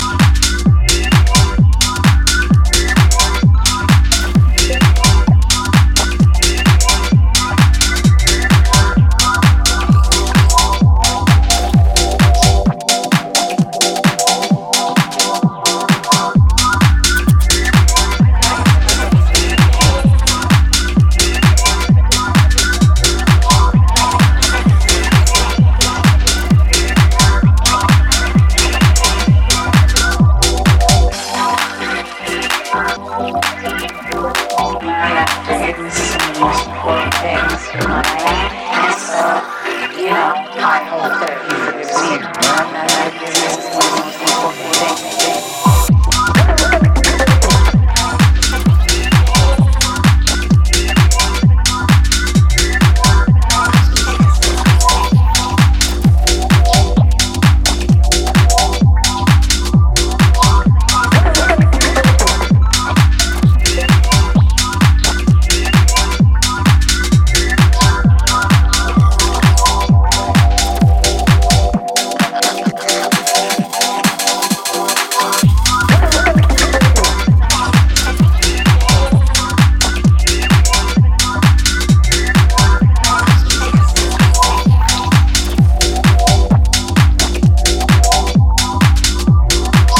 One of the UK’s most exciting house talents